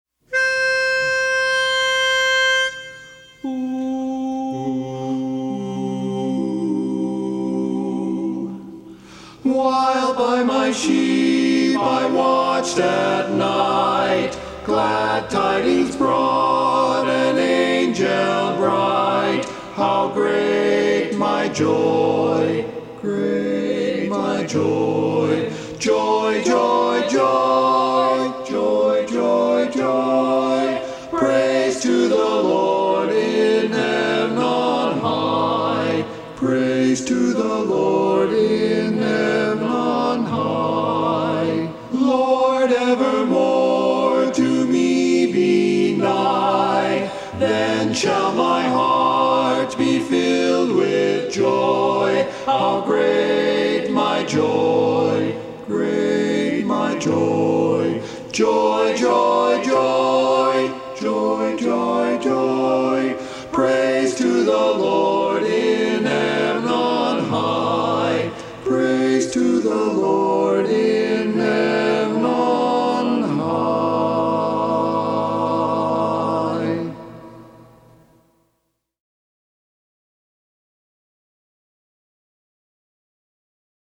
Christmas Songs
Barbershop
Bari